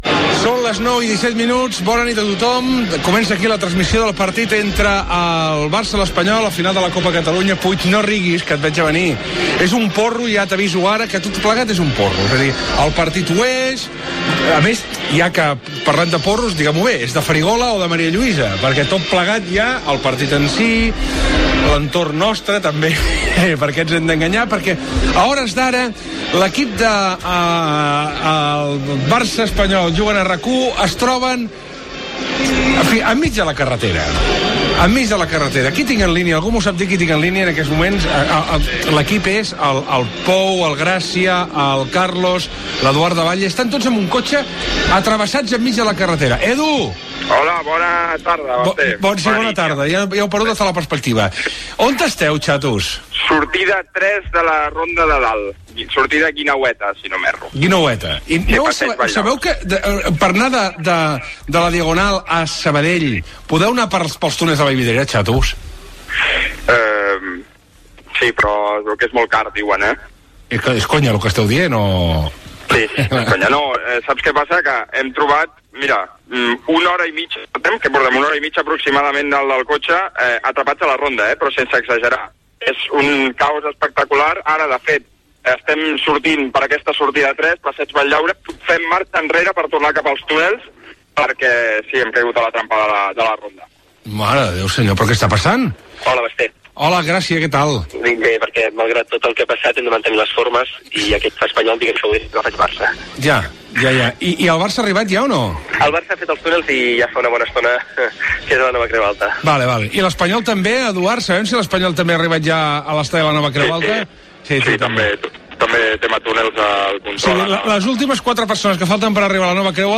Indicatiu del programa, diàleg amb l'equip que encara està circulant en cotxe, missatges de l'audiència, indicatiu de la ràdio, nou contacte amb el cotxe de l'equip de la transmissió, hora, informació des de Dortmund, comentaris sobre el partit de la Nova Creu Alta i l'ambient que hi ha previ al partit.